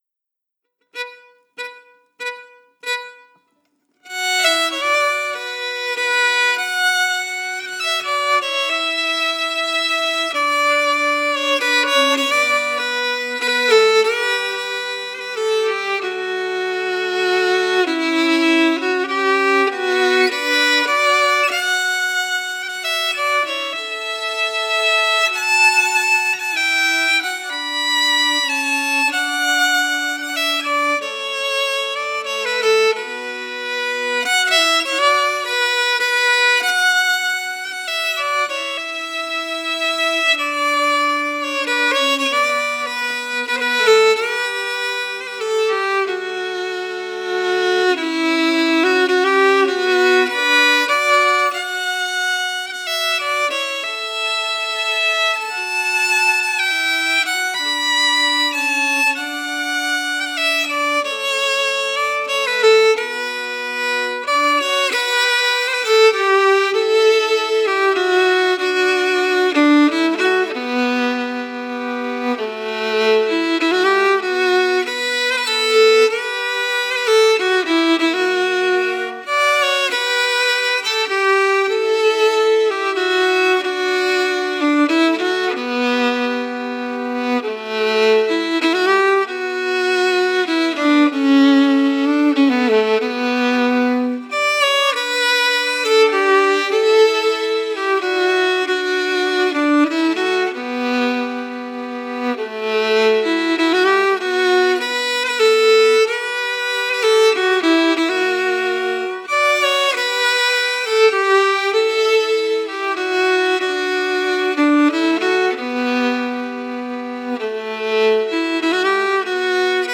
Key: Bm
Form: Slow Air
MP3: Melody Emphasis
LakeChamplain-MelodyEmphasis.mp3